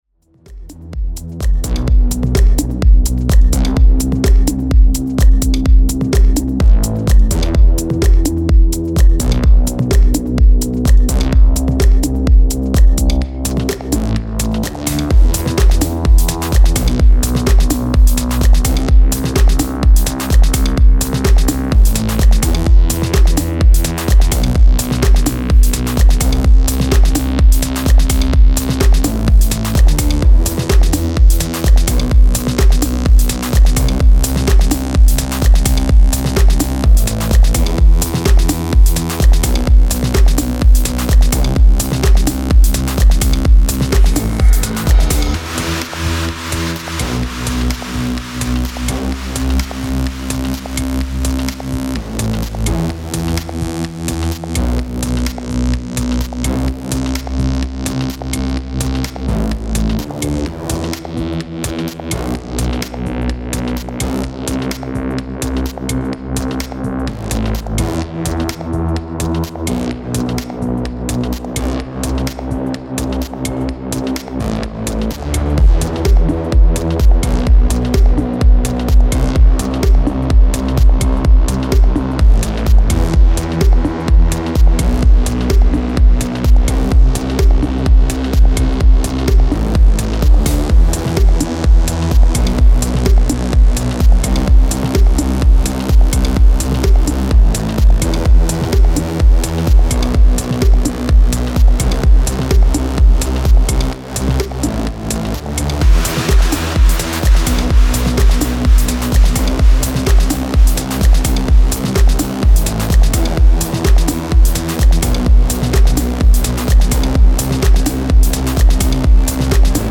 Style: Tech House